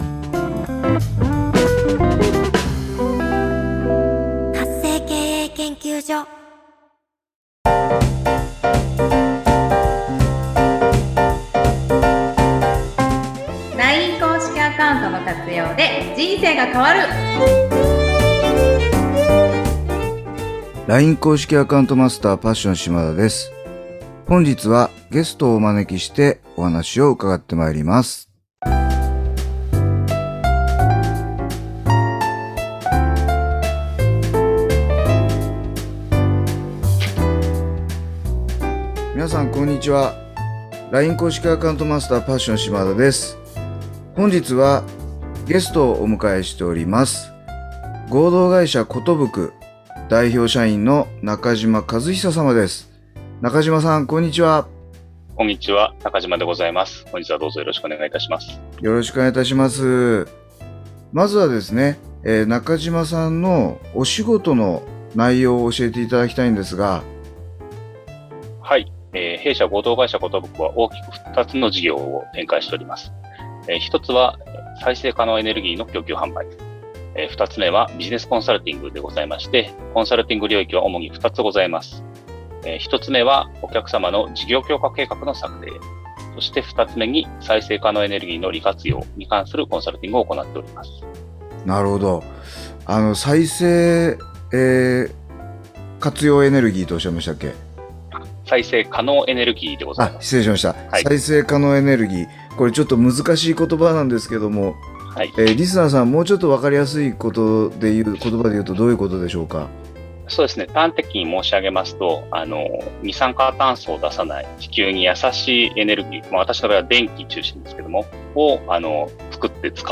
今回はゲストトークと題して、様々な業界の皆様をゲストでお招きし どんなお仕事をされているのか、そしてそのお仕事に対してLINE公式アカウントをどのように活用されているのかをお伺いさせていただきます。